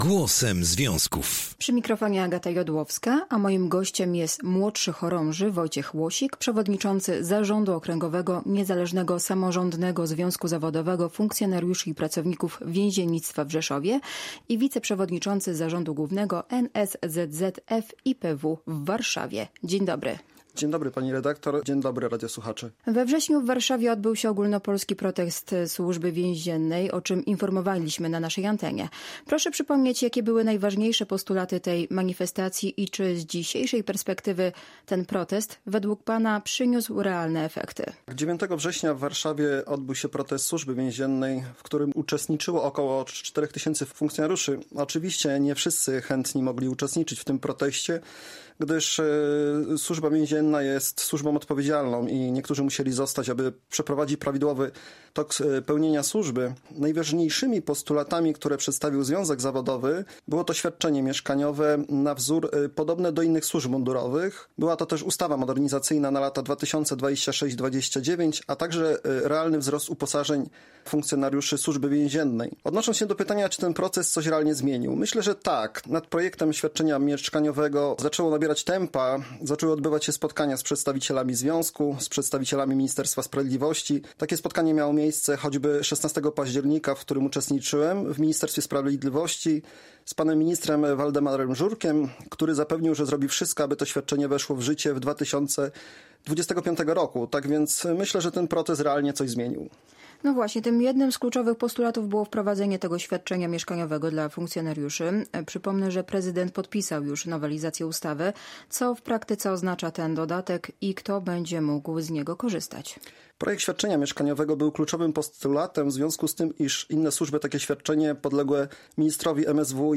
Związkowcy mówią o efektach • Audycje • Polskie Radio Rzeszów